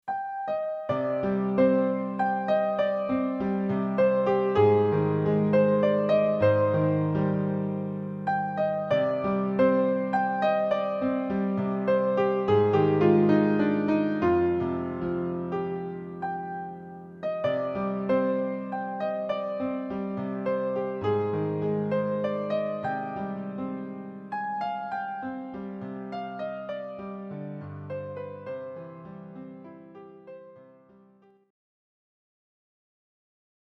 poetic and melodic music
played on the sampled piano.
element to the emotional and evocative music.
on Roland, Alesis, and Yamaha synthesizers.